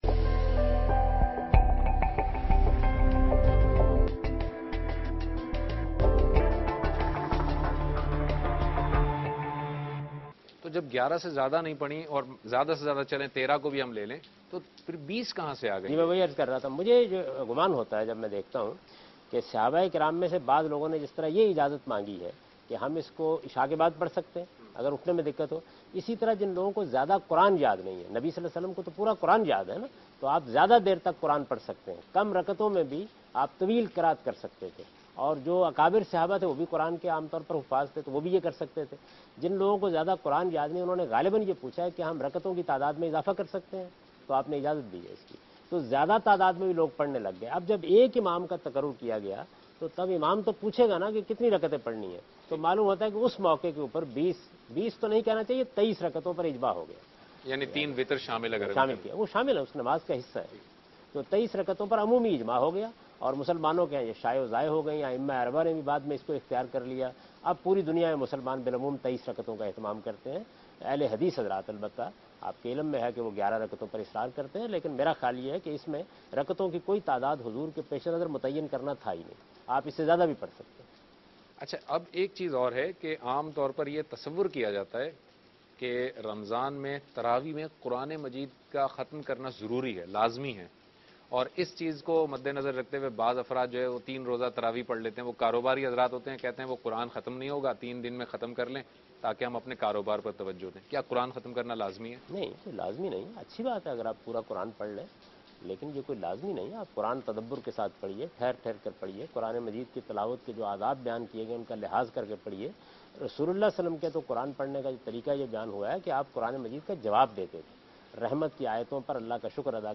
Category: TV Programs / Dunya News / Deen-o-Daanish / Questions_Answers /
دنیا ٹی وی کے پروگرام دین ودانش میں جاوید احمد غامدی تراویح کی حقیقت کے متعلق گفتگو کر رہے ہیں